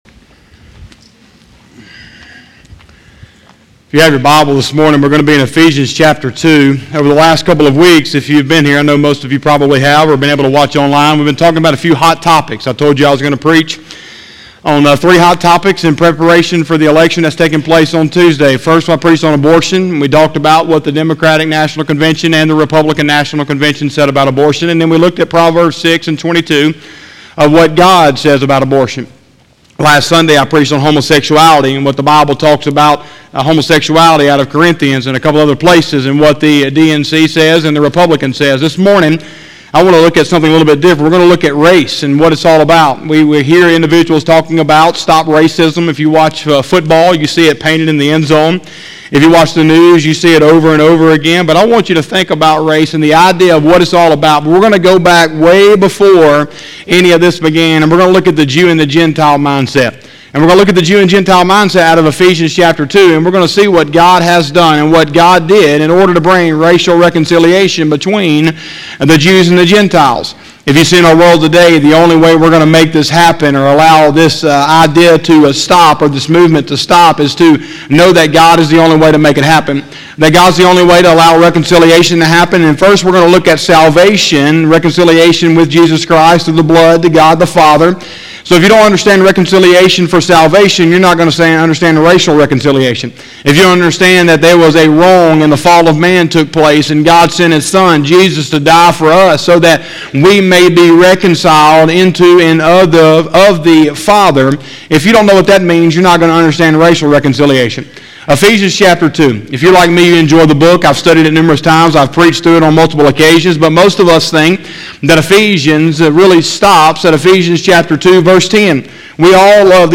11/01/2020 – Sunday Morning Service